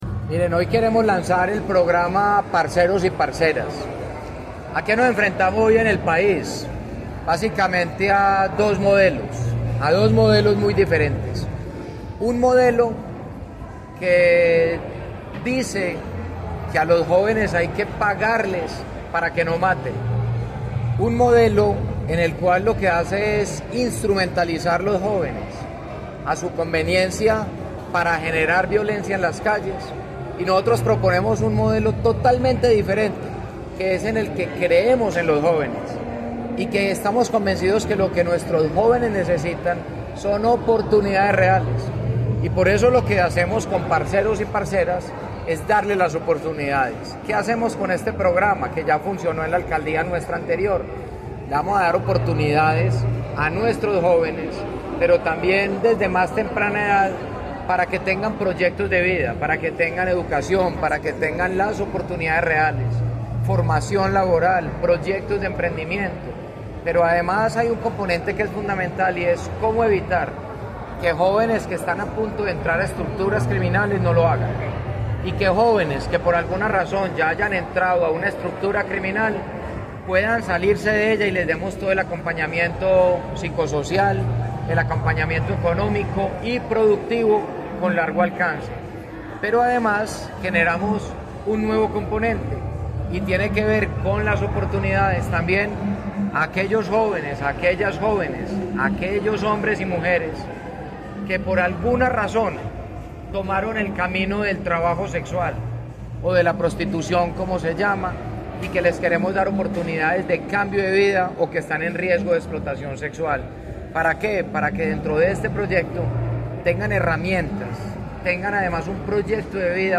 Palabras de Federico Gutiérrez, alcalde de Medellín